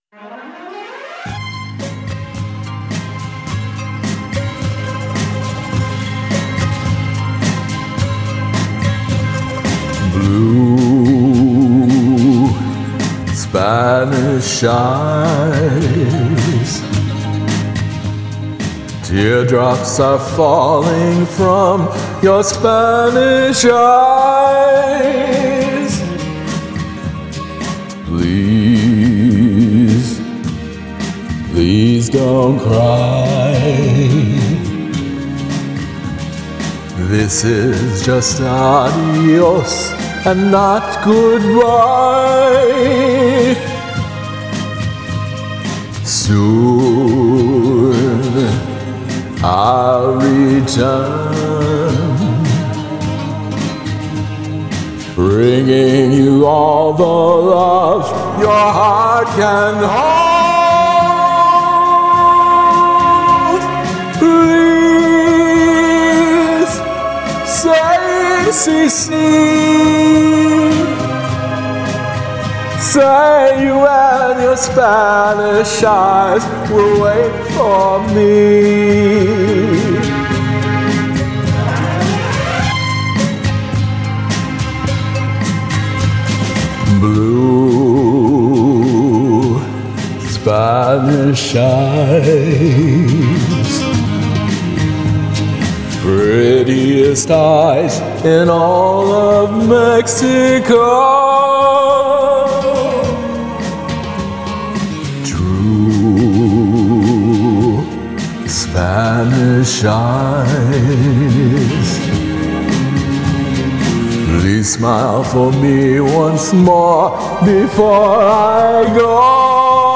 I love the steady pulse of the drums!